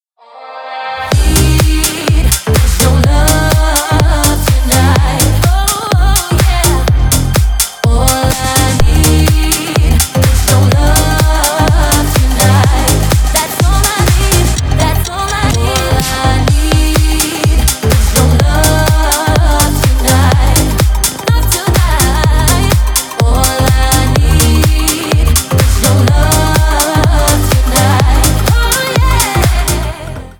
клубные # кавер